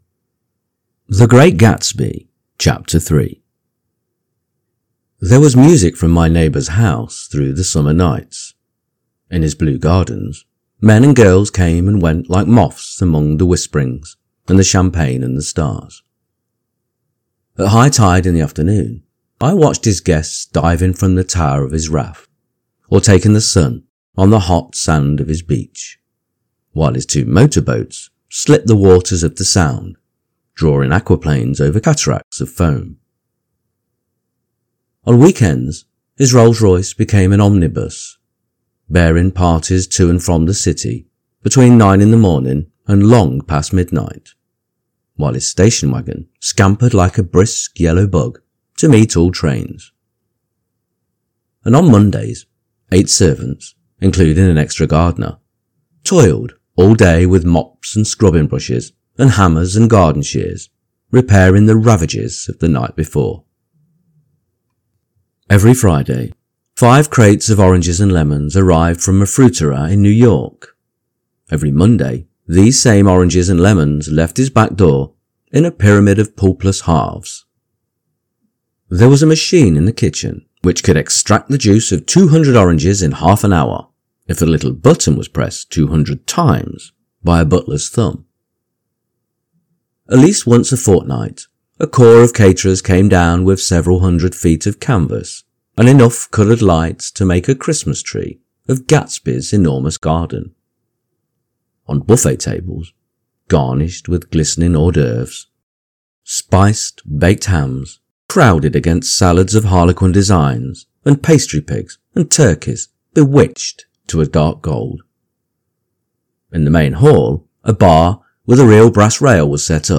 The Great Gatsby Audio-book – Chapter 3 | Soft Spoken English Male Full Reading (F.Scott Fitzgerald) - Dynamic Daydreaming